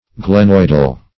glenoidal - definition of glenoidal - synonyms, pronunciation, spelling from Free Dictionary Search Result for " glenoidal" : The Collaborative International Dictionary of English v.0.48: Glenoidal \Gle*noid"al\, a. (Anat.)